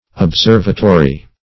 Observatory \Ob*serv"a*to*ry\, n.; pl.
observatory.mp3